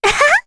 Rehartna-Vox-Laugh2_kr.wav